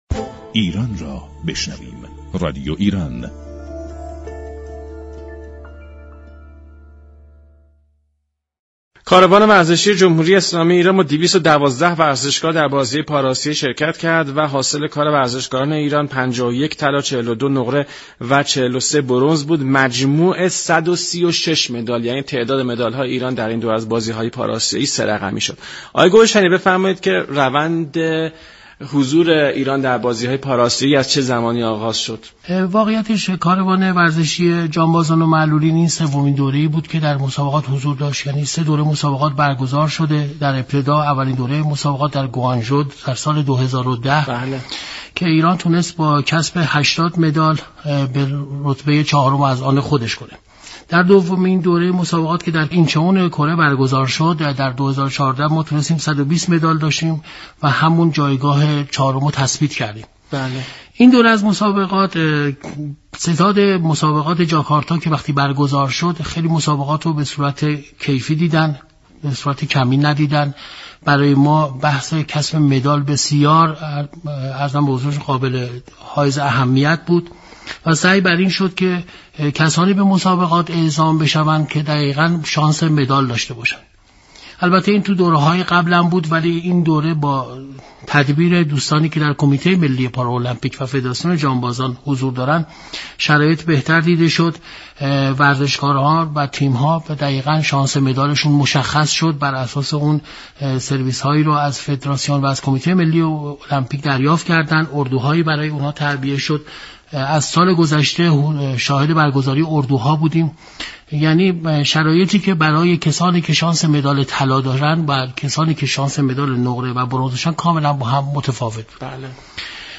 گفت و گو با برنامه «میزبان»